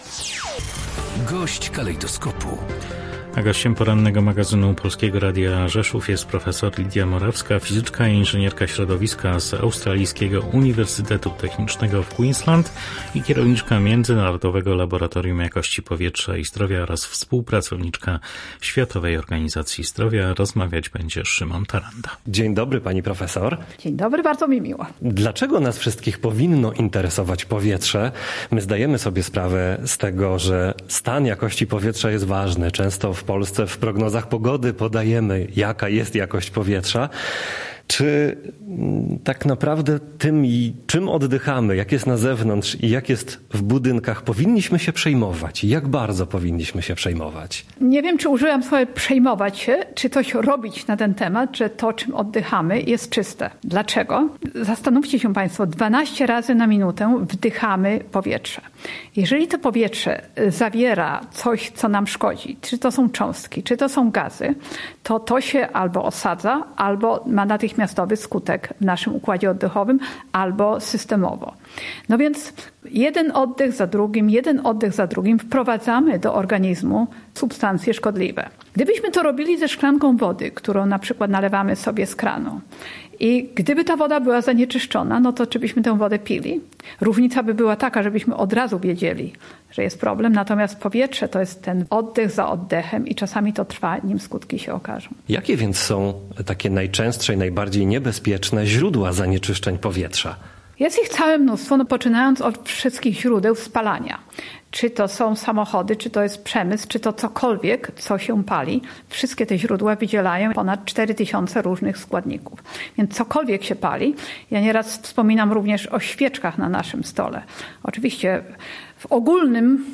– wyjaśnia gość Polskiego Radia Rzeszów.